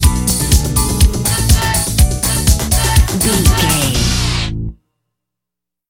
Dorian
Fast
drum machine
synthesiser
electric piano